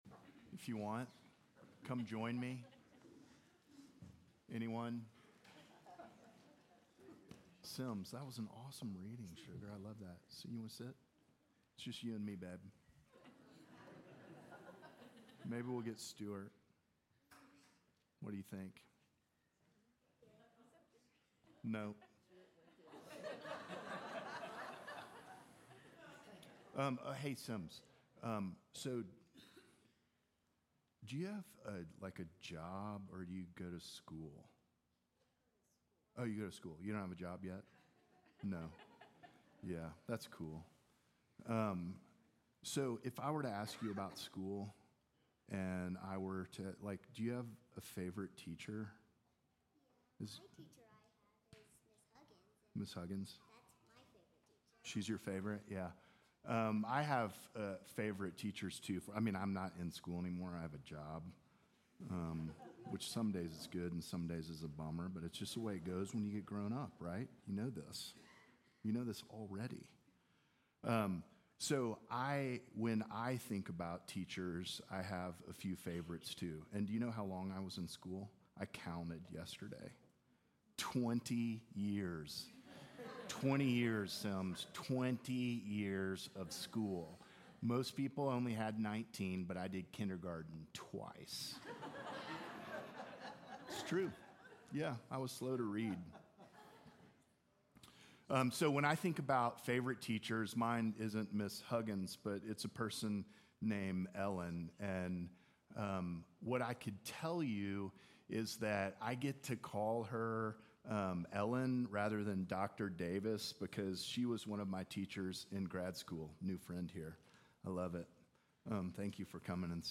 First Sunday in Lent Family Service
Sermons